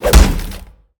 shield-hit-11.ogg